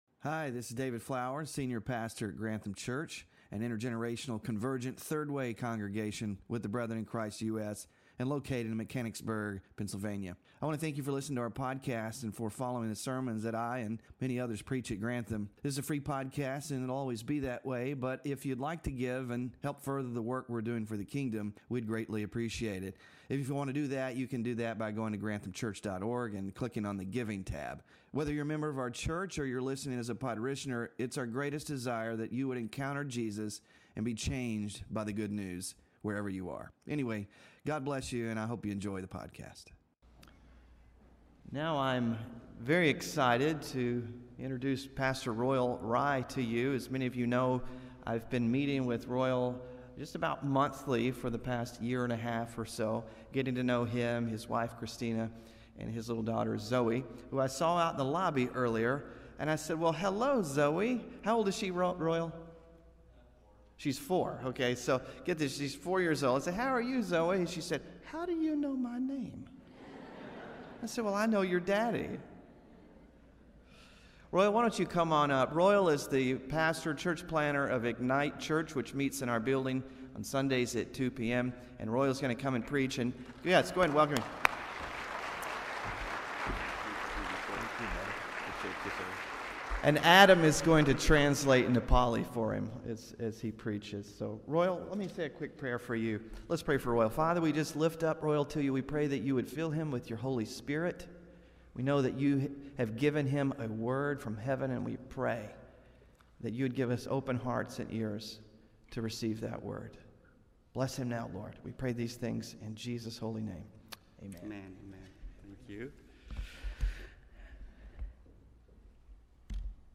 WORSHIP RESOURCES FANNING THE FLAME SERMON SLIDES (11-17-24) SMALL GROUP DISCUSSION QUESTIONS (11-17-24) BULLETIN (11-17-24)